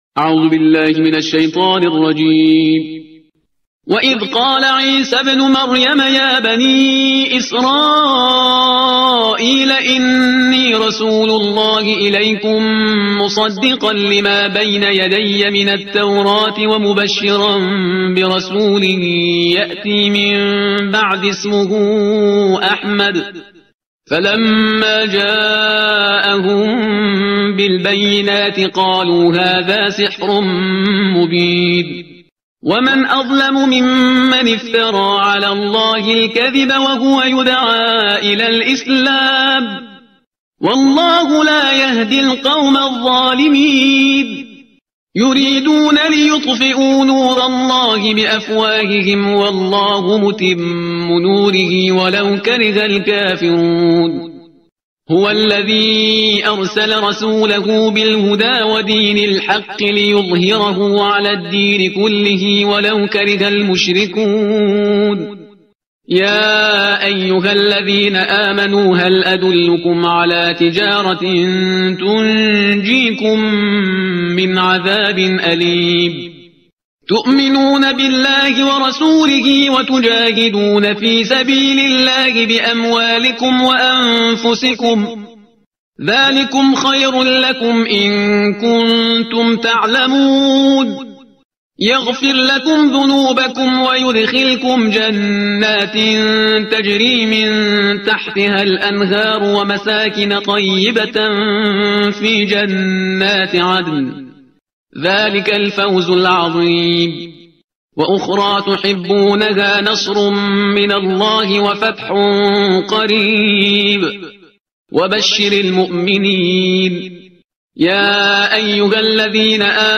ترتیل صفحه 552 قرآن با صدای شهریار پرهیزگار